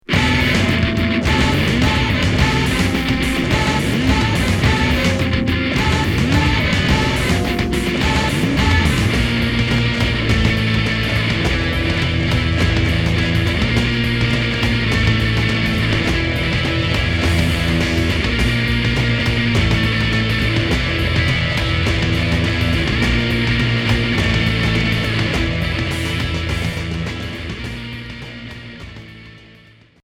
Noise core